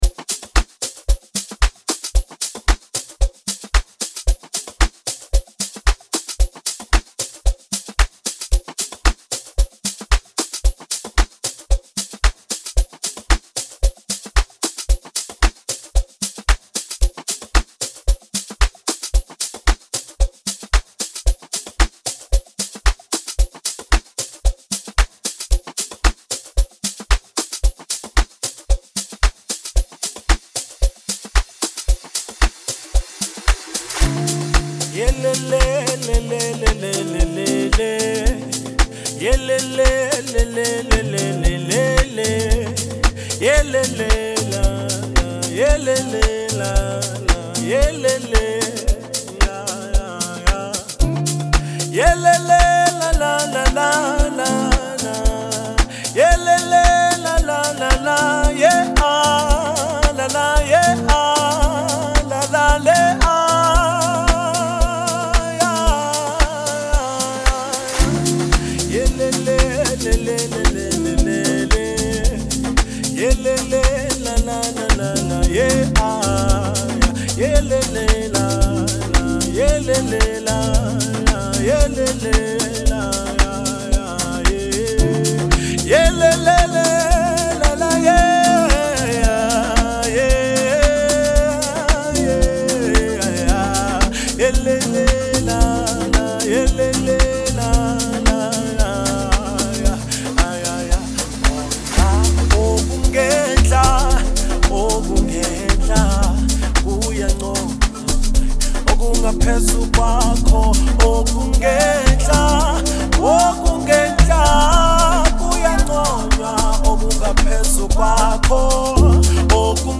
he is very good with energetic vibe